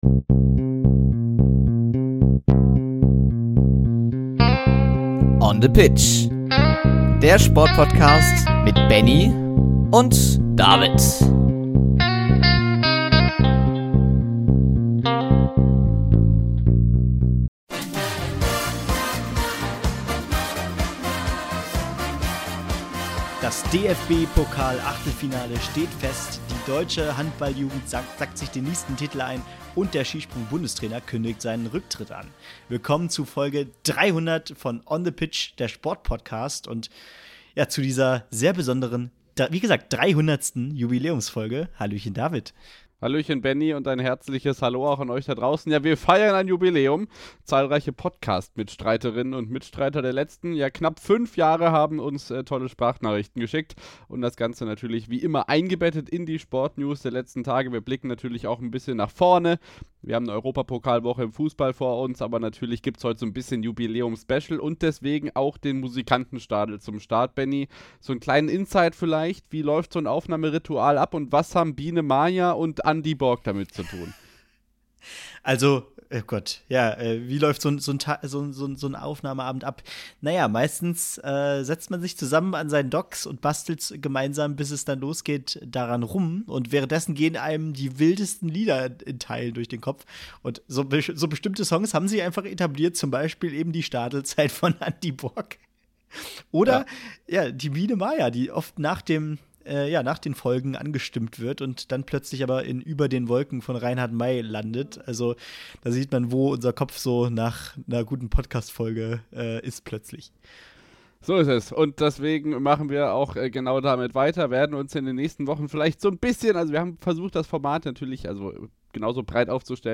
In dieser Folge blicken wir nicht nur zurück auf die besonderen Momente der letzten fast fünf Jahre, sondern liefern auch die aktuellsten Sport-News aus Fußball, Handball, Wintersport, Tennis, NBA, NFL, Leichtathletik, Darts, Snooker, Radsport und Motorsport. Besonders: Wir haben neun persönliche Grußbotschaften von Mitstreitern, Wegbegleitern und sogar ein bisschen Prominenz eingebaut – kleine Stimmen, die die Geschichte von On the Pitch mitprägen und diese Jubiläumsfolge zu etwas ganz Besonderem machen. Darüber hinaus gibt es einen Blick in die Zukunft des Podcasts: Wir verraten, wie sich das Format künftig entwickeln wird.